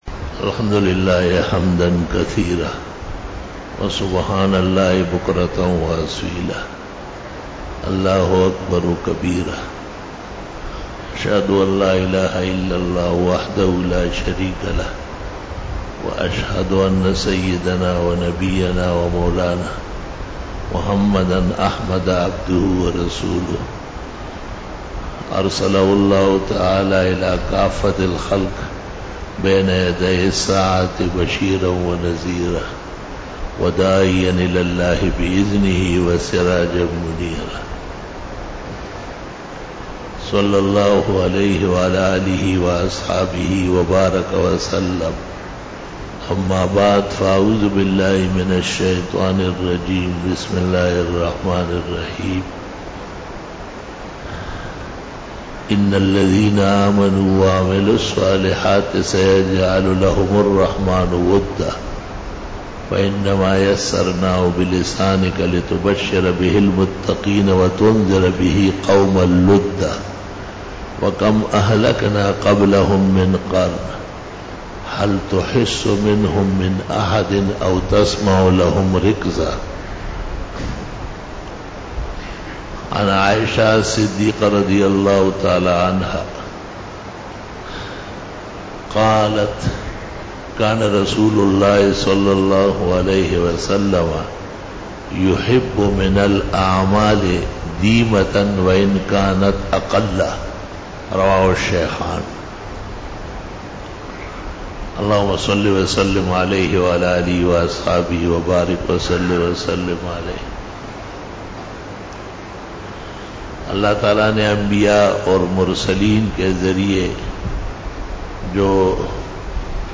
بیان جمعۃ المبارک
Khitab-e-Jummah